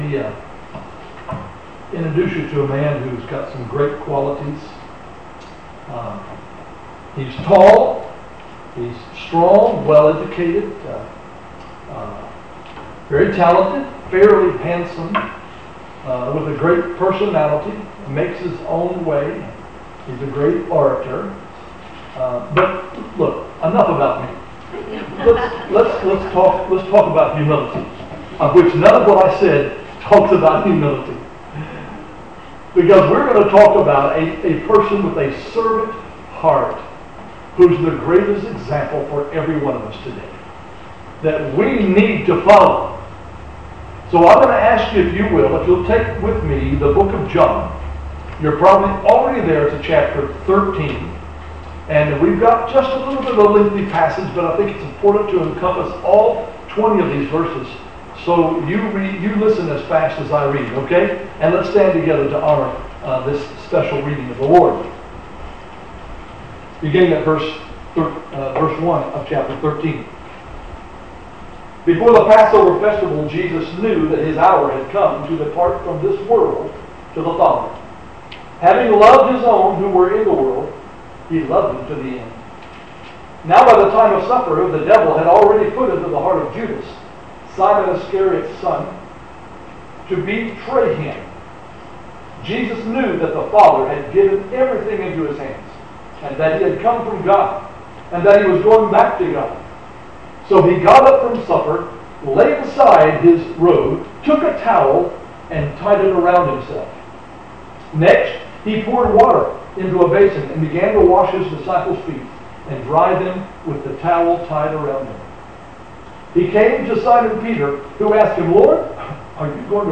Sermons - Jackson Ridge Baptist Church